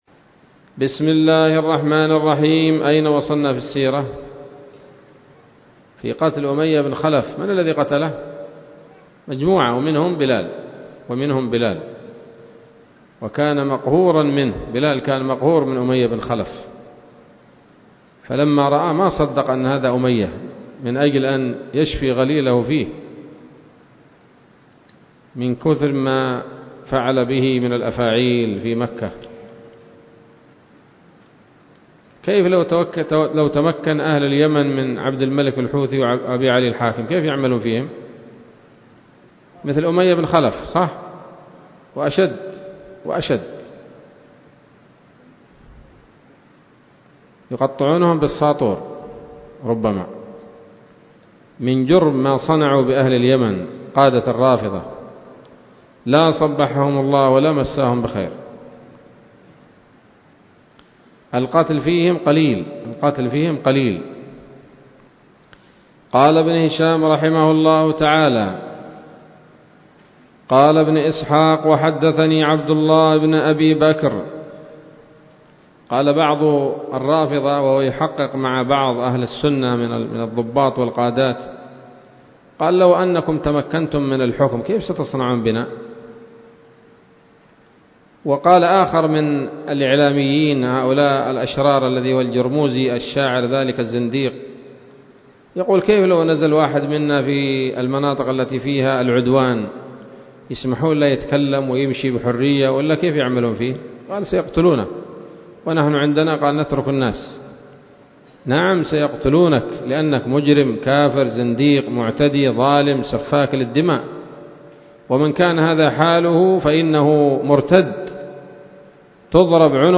الدرس السابع عشر بعد المائة من التعليق على كتاب السيرة النبوية لابن هشام